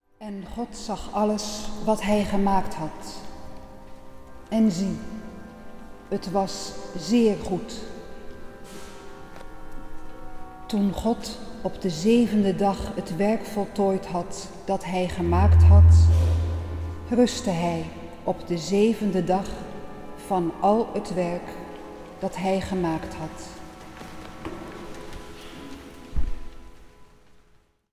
orgel
piano
viool
Strijkensemble
dwarsfluit
hobo.
Zang | Gemengd koor